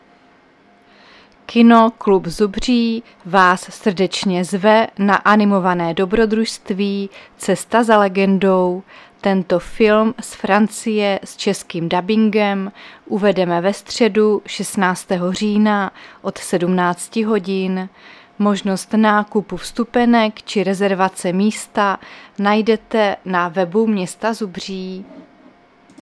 Záznam hlášení místního rozhlasu 16.10.2024
Zařazení: Rozhlas